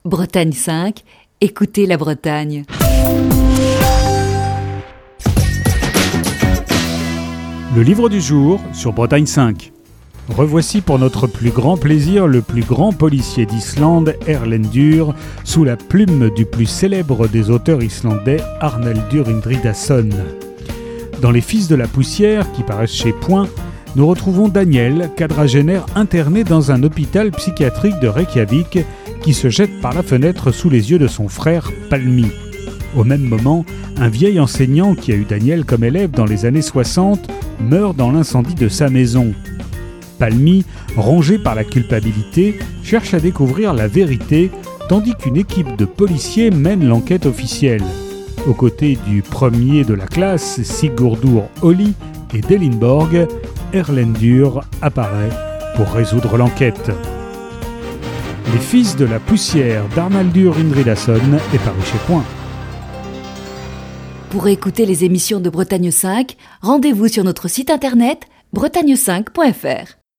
Chronique du 3 janvier 2020.